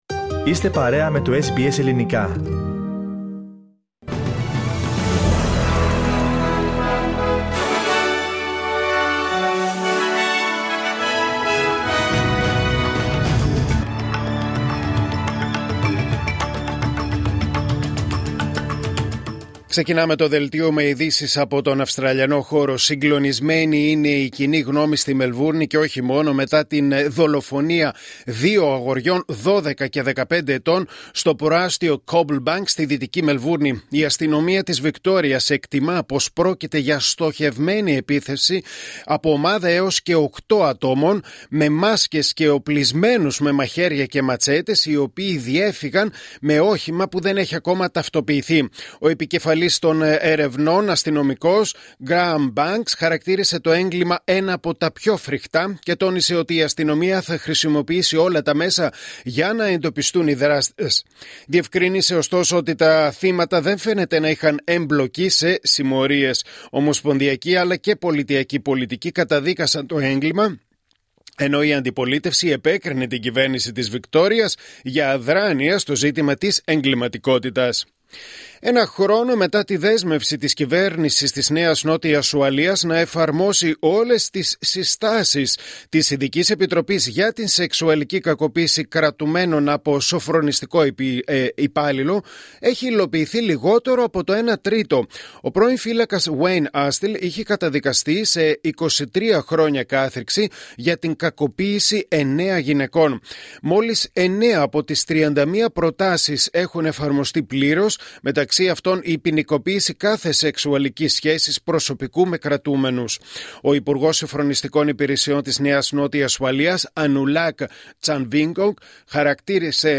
Δελτίο Ειδήσεων Κυριακή 7 Σεπτεμβρίου 2025